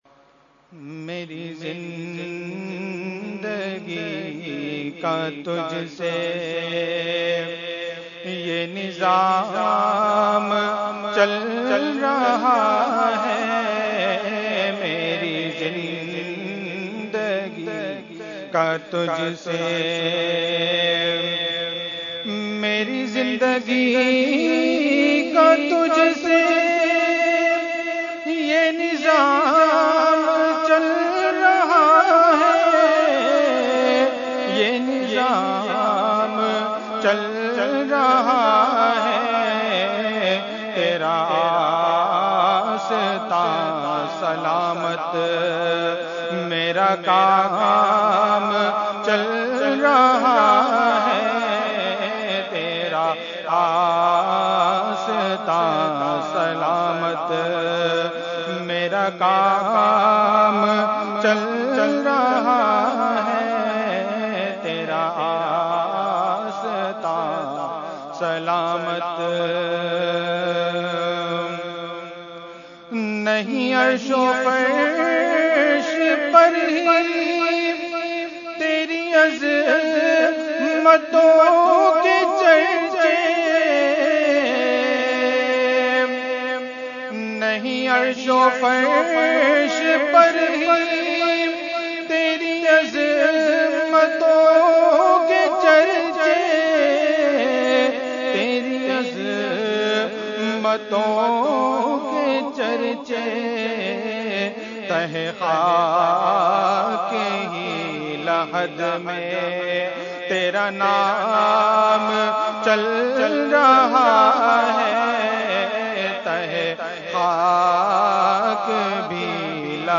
Category : Naat | Language : UrduEvent : Khatmul Quran 2017